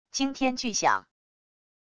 惊天巨响wav音频